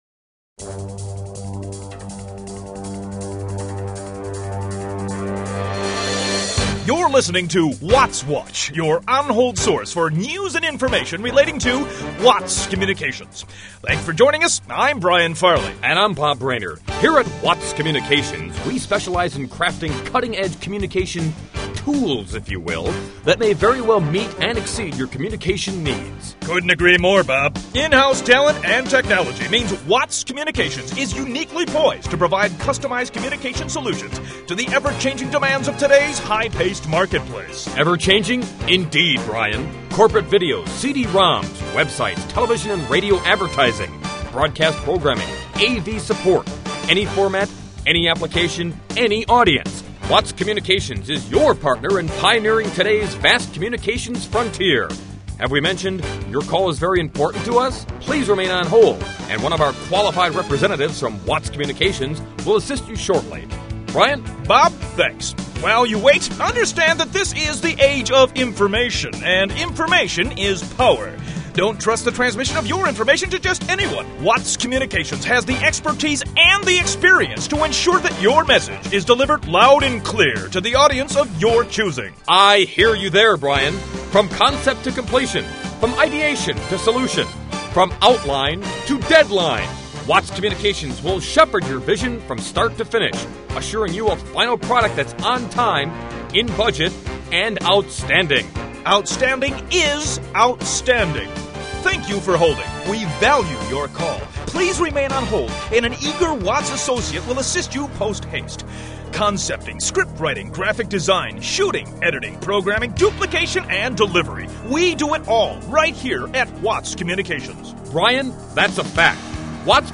Watts News Duo On-Hold Messaging